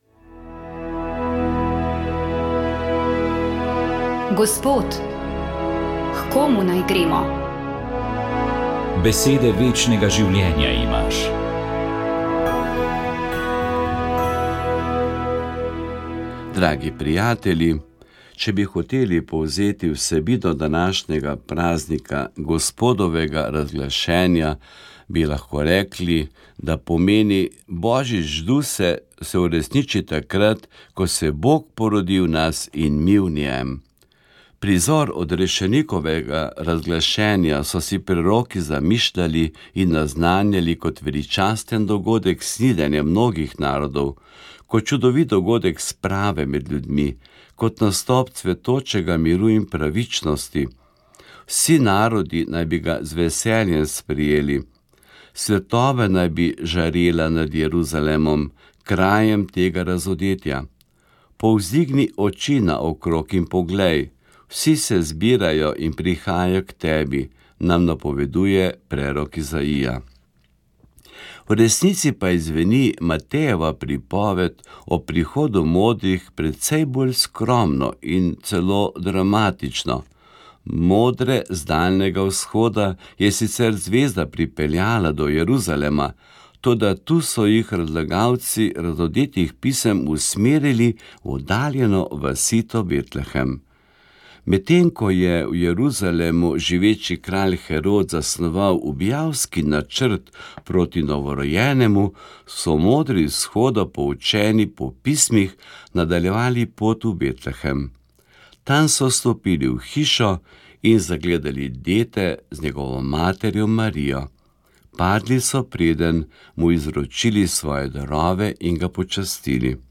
Duhovni nagovor
V nagovoru škof osvetli pomen blagoslova ob novem letu in dnevu miru, ki prinaša varstvo, milost ter mir. Reflektira o simboliki peščene ure, ki spominja na minljivost časa, in izpostavlja, kako pretekle izkušnje oblikujejo našo sedanjost ter pripravijo prihodnost.